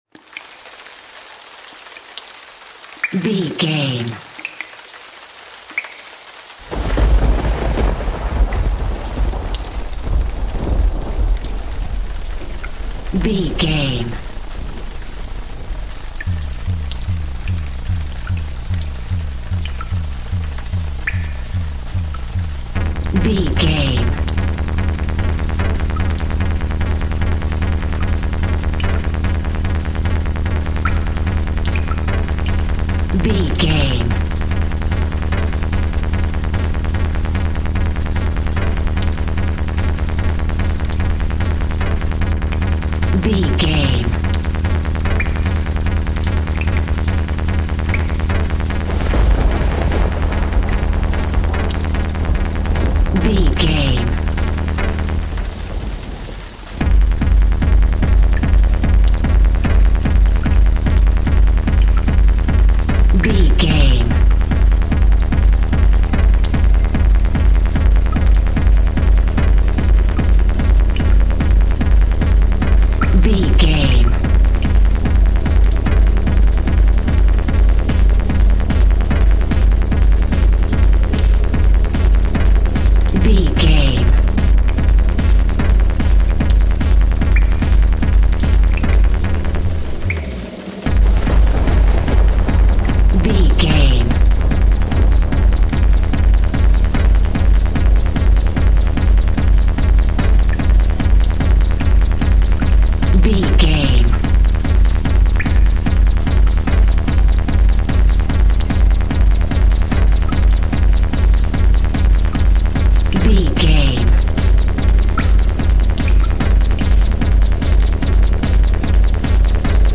Storm Electronic Music.
Epic / Action
Fast paced
Atonal
Fast
aggressive
dark
driving
energetic
intense
synthesiser
drum machine
techno
industrial
glitch
synth lead
synth bass
Synth Pads